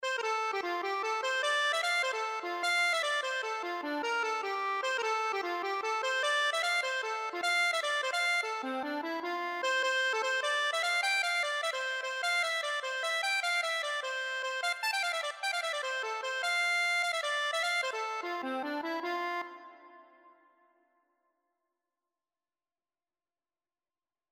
(Irish Folk Song) Accordion version
F major (Sounding Pitch) (View more F major Music for Accordion )
6/8 (View more 6/8 Music)
Accordion  (View more Easy Accordion Music)
Traditional (View more Traditional Accordion Music)
leave_you_in_sadness_ON607_ACC.mp3